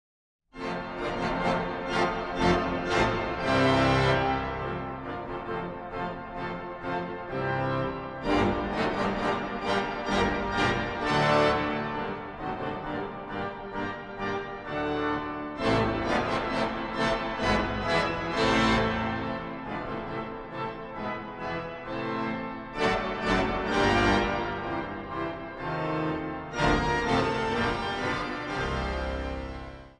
orgel
trompet
trombone
pauken en slagwerk
hoorn.